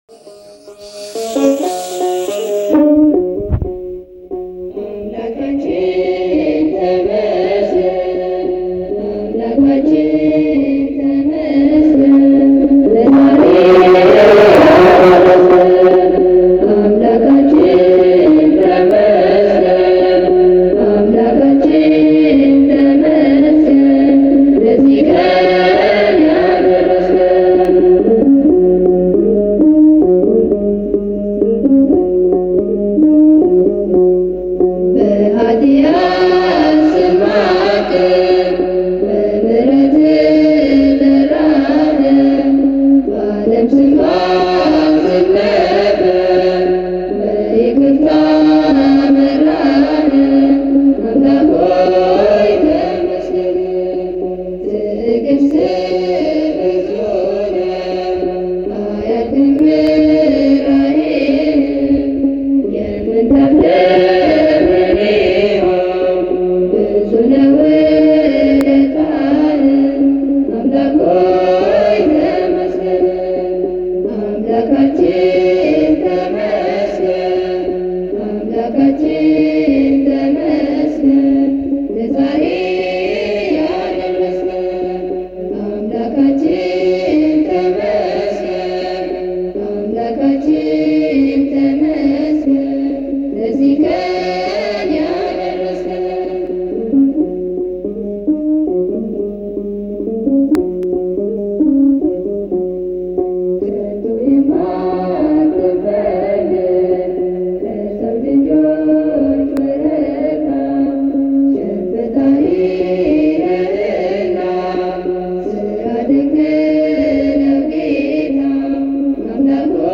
መዝሙር (አምላካችን ተመስገን) August 5, 2018